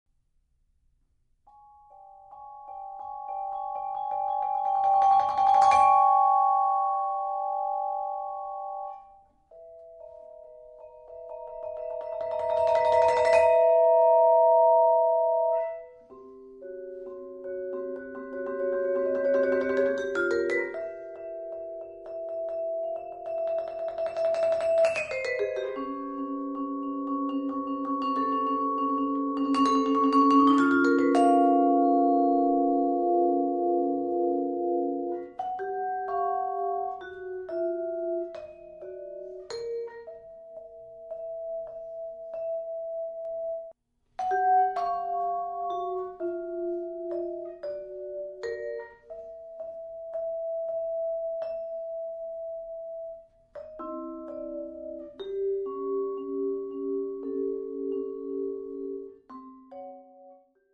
solo vibraphone
four mallets, and offers